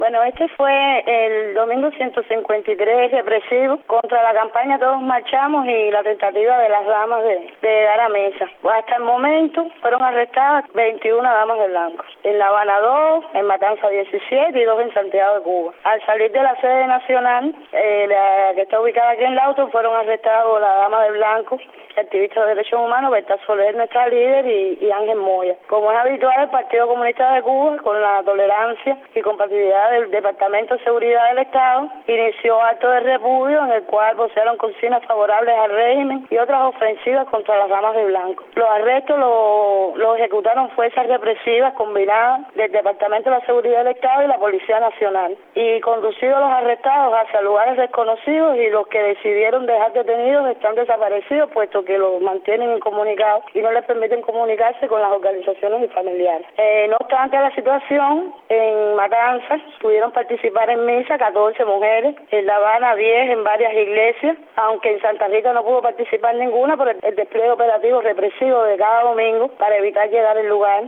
[Con entrevista